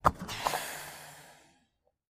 tr_com_gastank_rel_01_hpx
Nissan Sentra exterior point of view as gas cap and door opens and releases pressure.